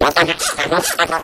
Media:tick_vo_07hurt.ogg Tick whimpers.
迪克啜泣
Tick_vo_07hurt.ogg